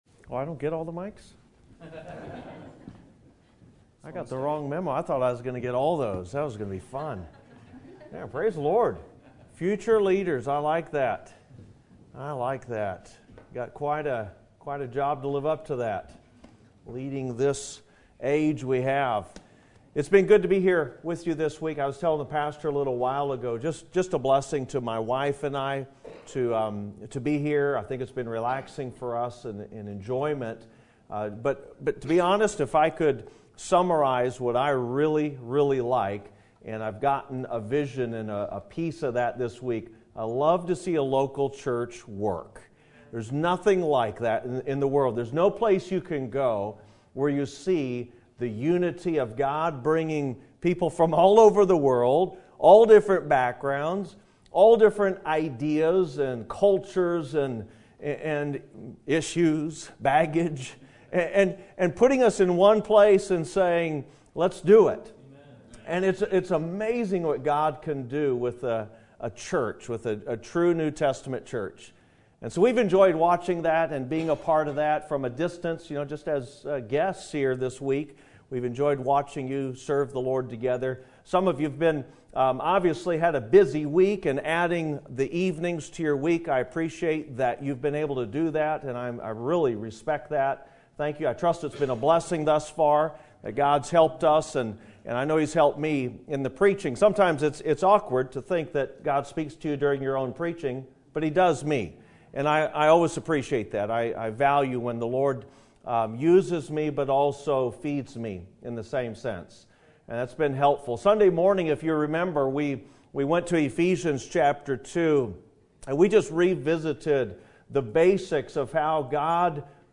Sermon Date
Sermon Topic: Spring Revival Sermon Type: Special Sermon Audio: Sermon download: Download (20.39 MB) Sermon Tags: Ephesians Revival Church Citizen